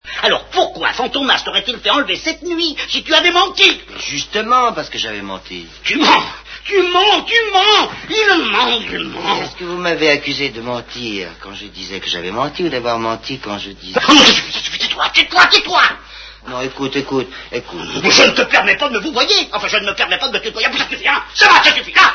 cet interrogatoire :mrgreen: (je ne sais pas si le lien fonctionnera).